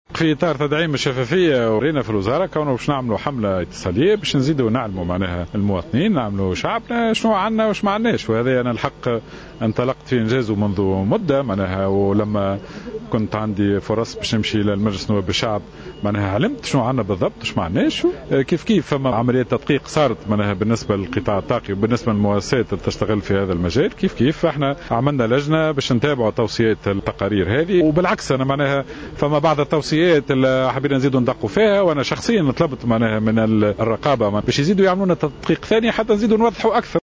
وبين الوزير في تصريح إعلامي اليوم الاربعاء، على هامش زيارته لولاية الكاف أنه بادر شخصيا بطلب التدقيق في حقيقة الثروات في تونس من خلال تشكيل لجنة لدراسة التقارير حول الطاقة ليتم إعلام المواطنين بالحجم الحقيقي للثروات.